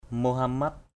/mo-ha-mat/ Mohamat _m%hMT [Cam M] (d.) thiên sứ Mohammad = Mahomet. Prophet Mohammad.